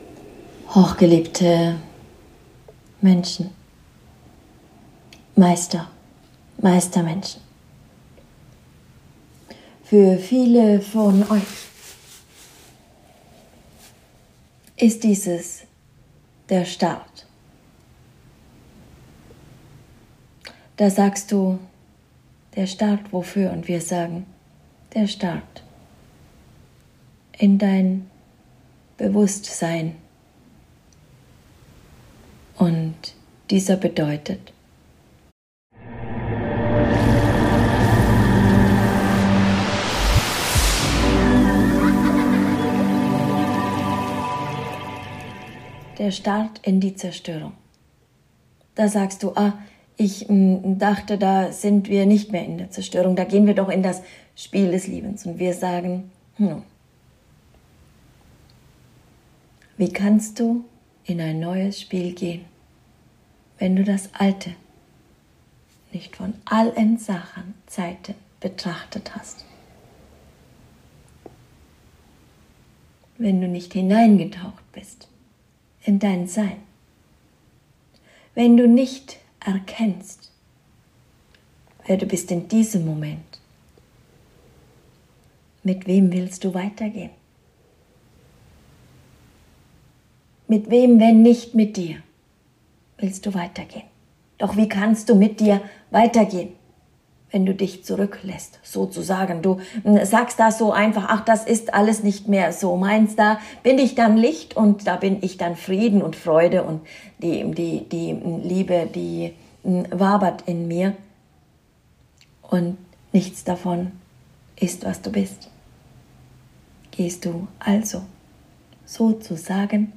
Channeling | Der Start für dein Sein!